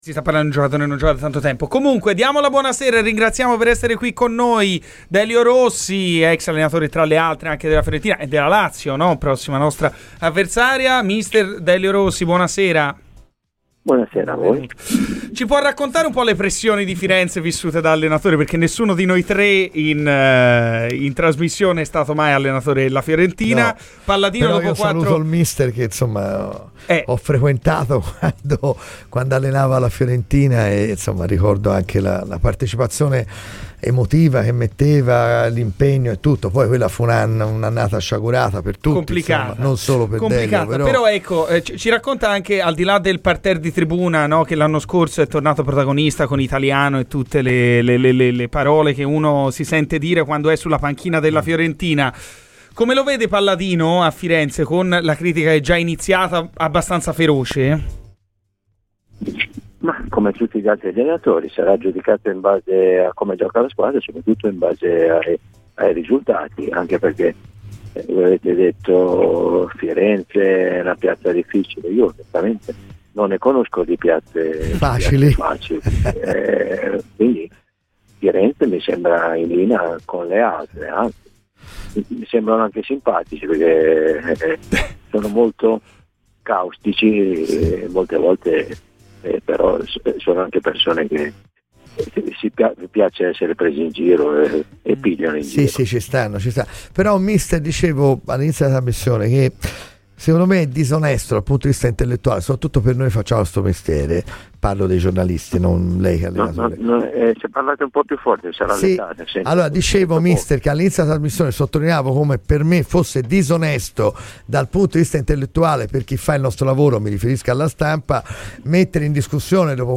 Delio Rossi, ex tecnico viola, ha parlato a Radio FirenzeViola durante Garrisca al Vento.